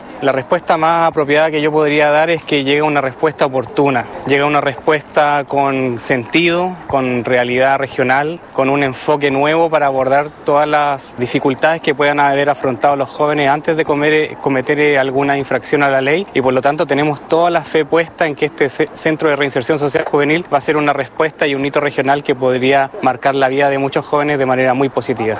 Ante ello, el seremi de Justicia y Derechos Humanos, Cristóbal Fuenzalida, dijo que la respuesta estatal que se da con la puesta en marcha del nuevo recinto, es oportuna.